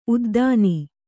♪ uddāni